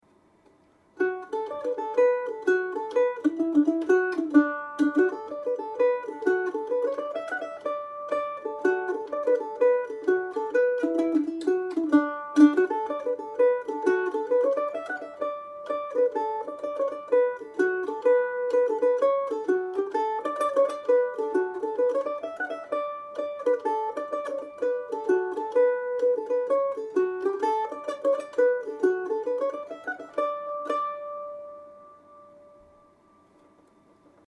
Mandolin players’ favourite sets